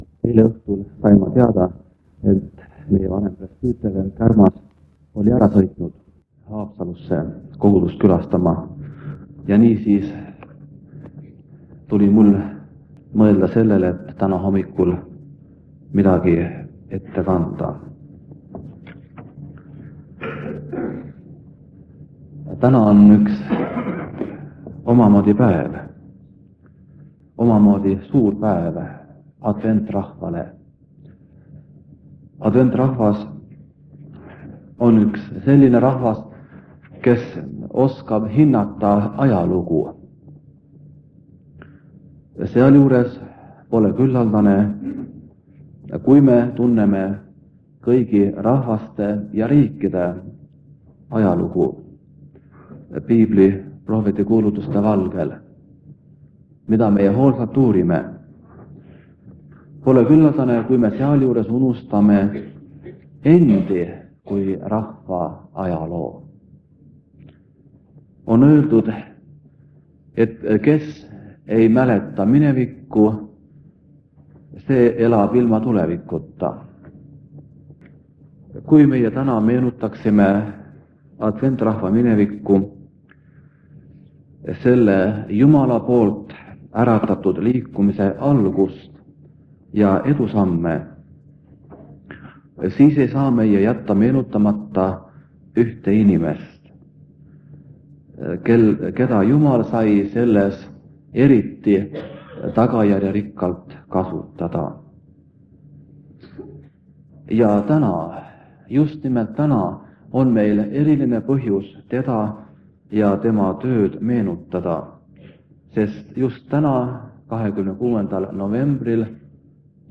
Jutlused
Koosolek vanalt lintmaki lindilt.